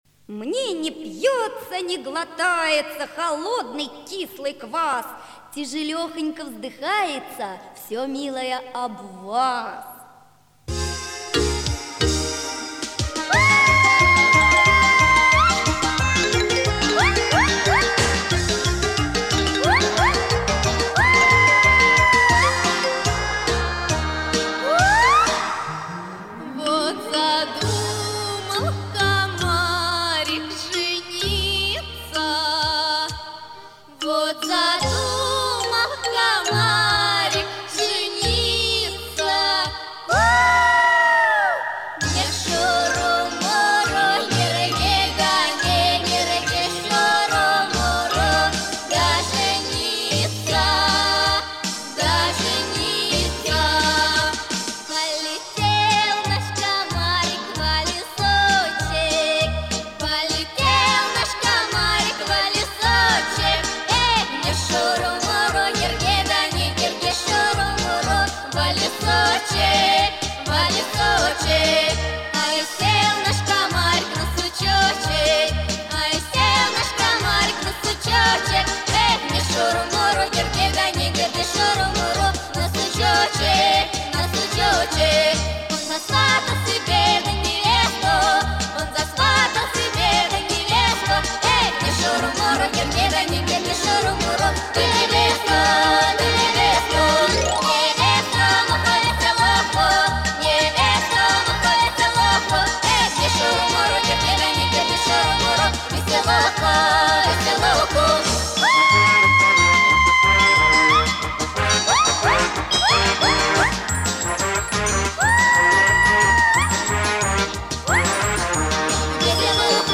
• Качество: Хорошее
• Категория: Детские песни